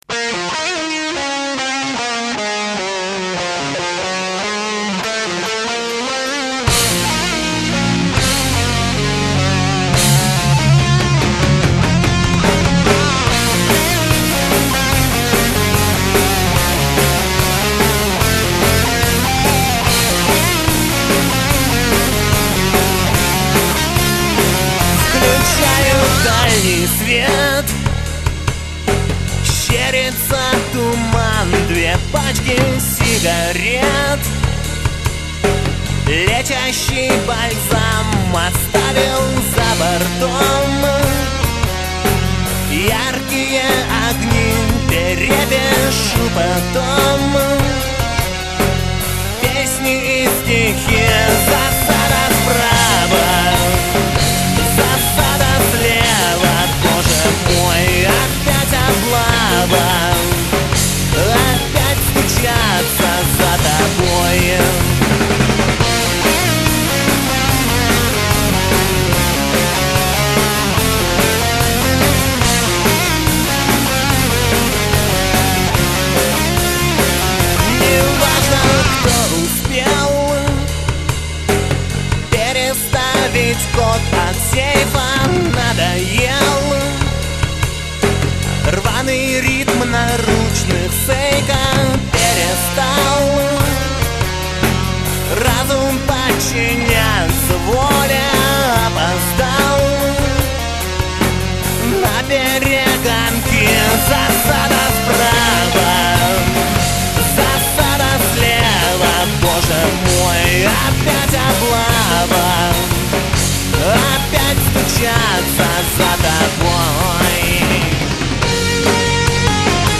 барабаны
бас
гитары
клавиши
голос, акустические гитары